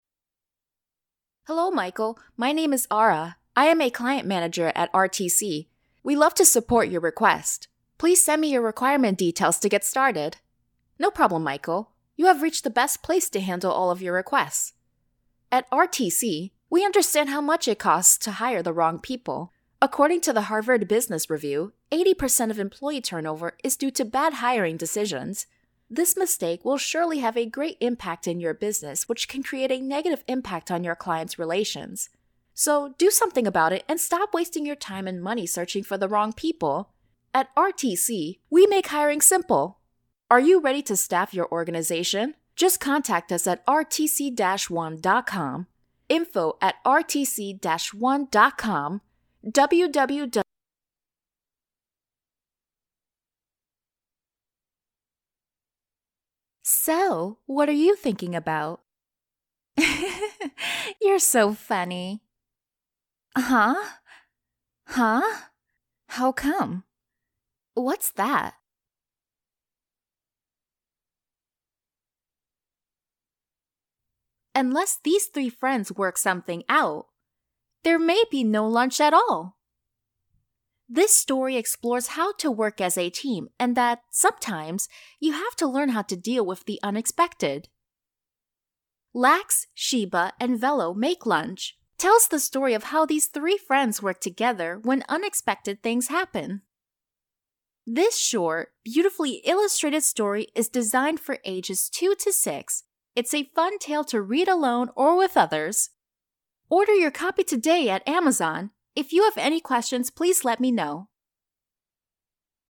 配音风格： 时尚 优雅 年轻 稳重 浑厚 知性 大气 亲切 可爱 亲切 可爱 自然